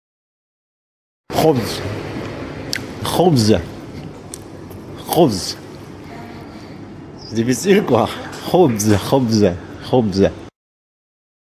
uitspraak Khobze-makla. uitspraak Khobze uitspraak